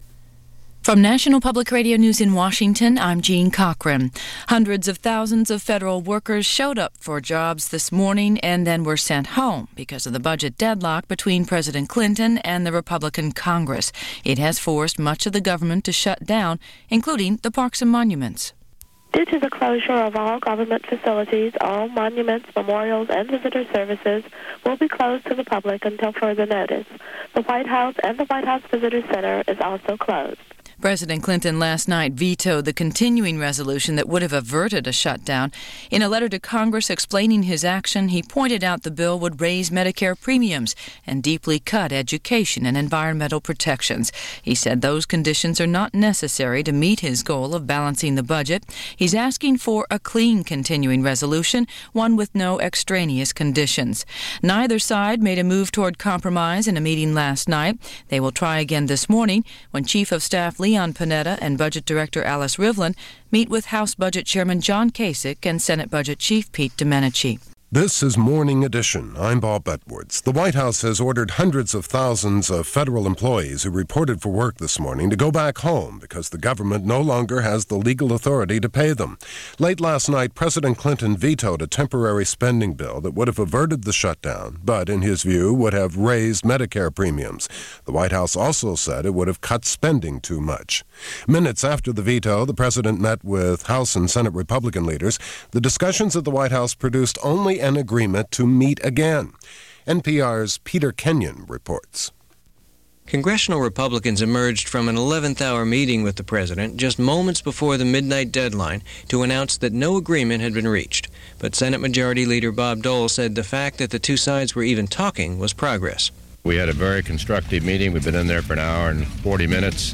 So here, as a reminder that Government Shutdowns are as American as Apple Pie, are news items from the 1995-1996 shut down and the 2013 shutdown.